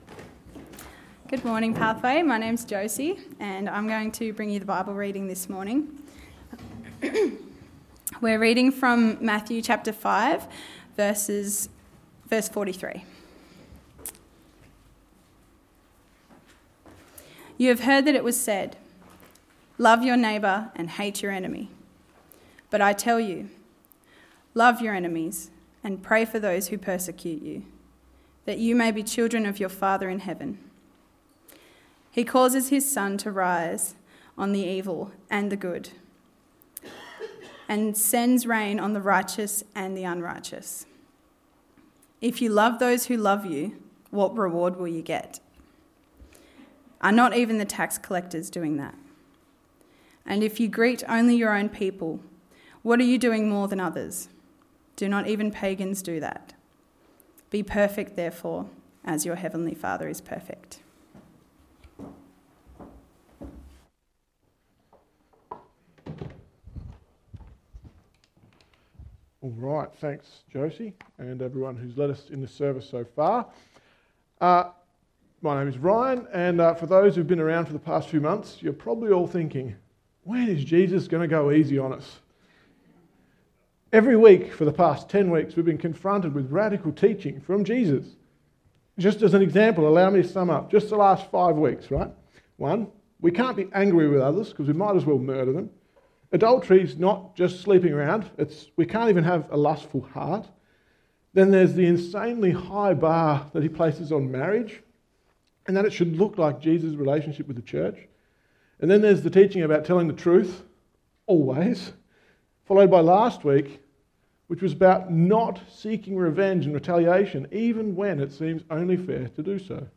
Sermon Series: Sermon on the Mount